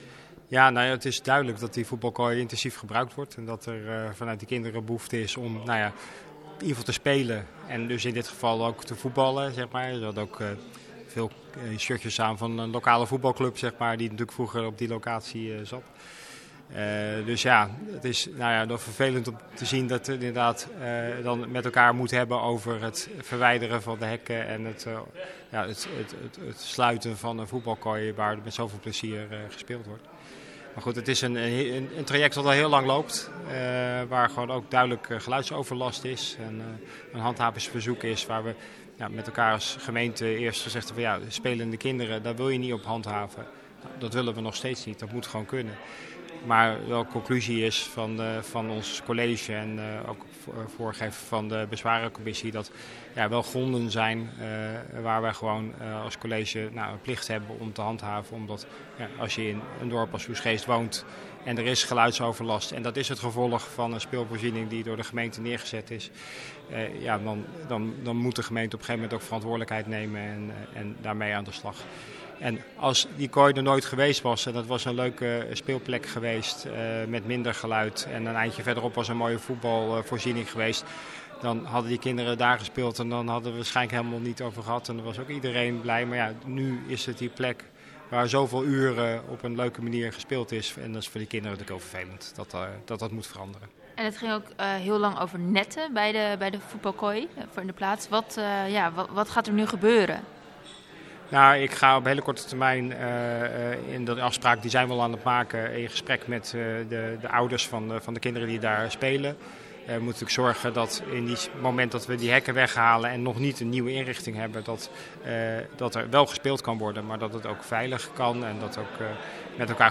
Wethouder Elfred Bus over de voetbalkooi in Oegstgeest.